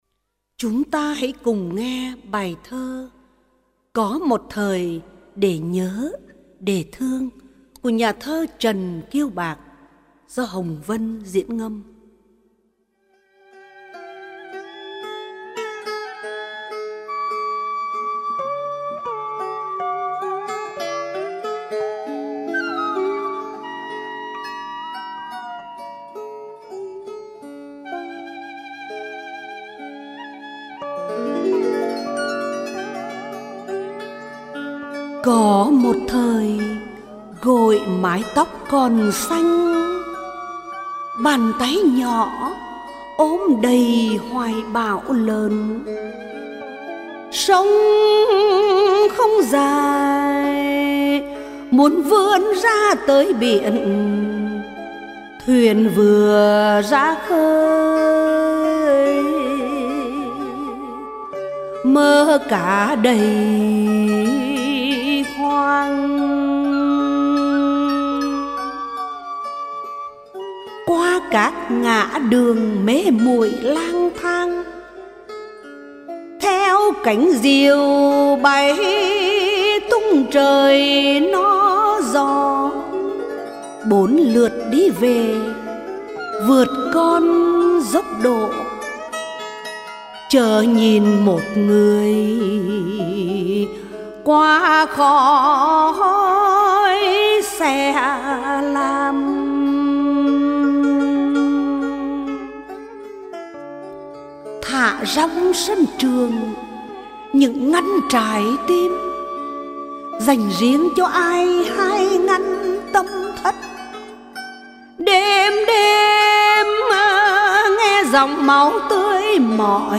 Ngâm Thơ